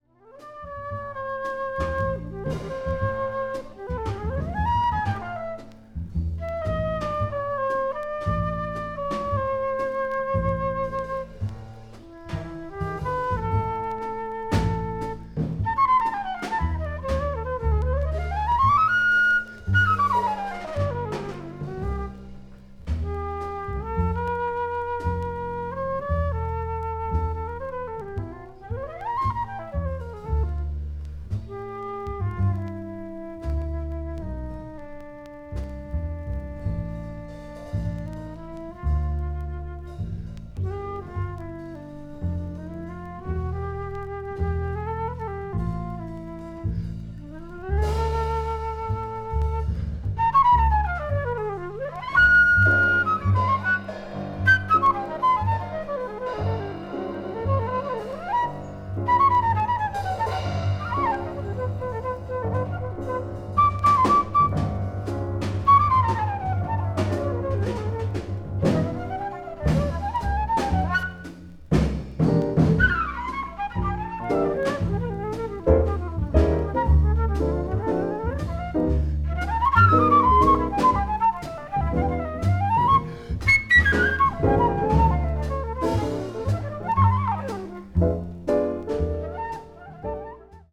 hard bop   modern jazz   post bop